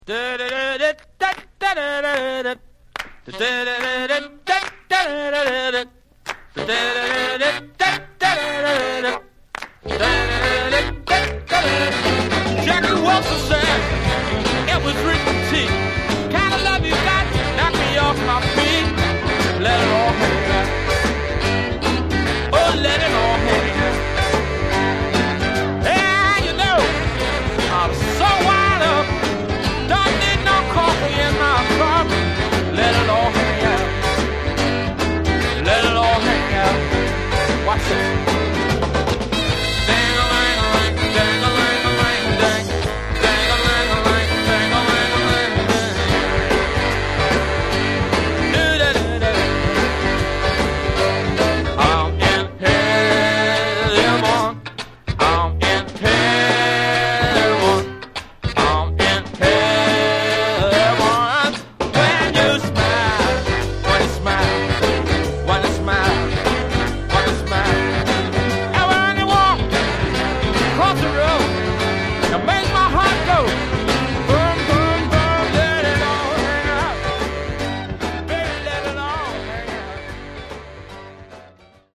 Genre: Other Northern Soul